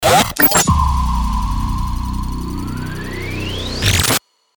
FX-1896-SCROLLER
FX-1896-SCROLLER.mp3